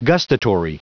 Prononciation du mot gustatory en anglais (fichier audio)
Prononciation du mot : gustatory